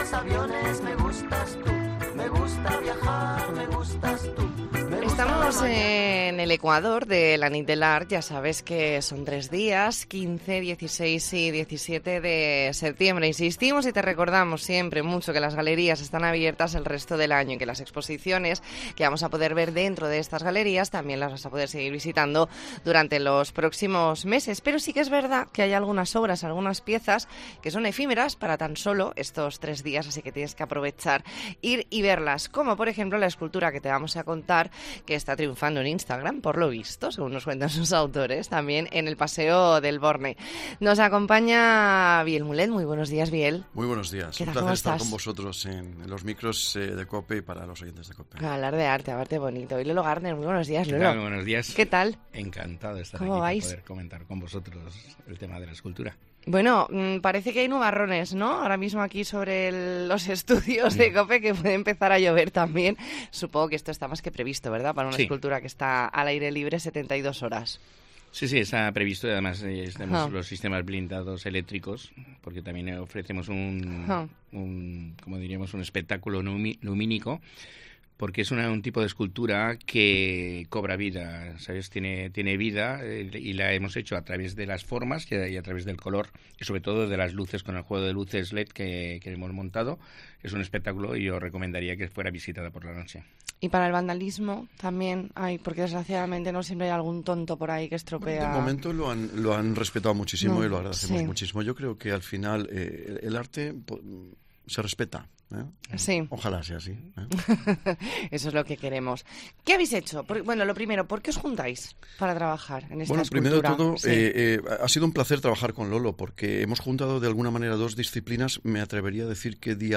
ntrevista en La Mañana en COPE Más Mallorca, viernes 16 de septiembre de 2022.